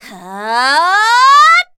assassin_w_voc_attack04_d.ogg